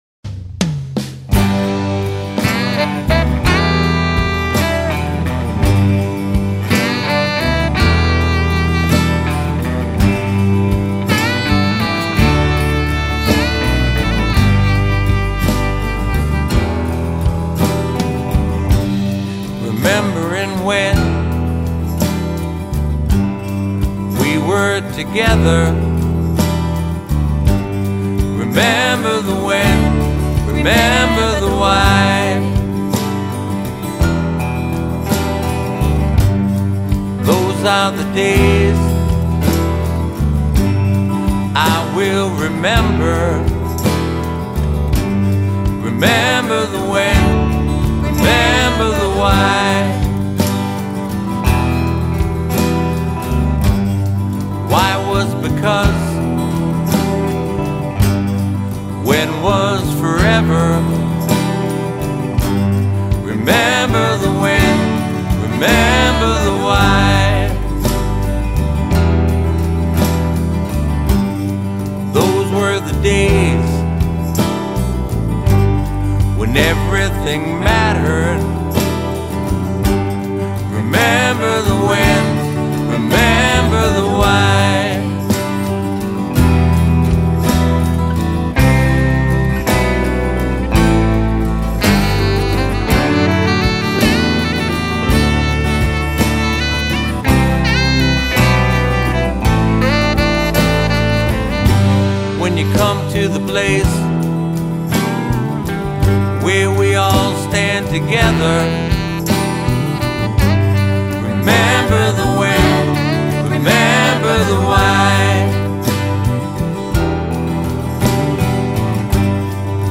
keys
drums, backup vocals
percussion
bass
sax, flute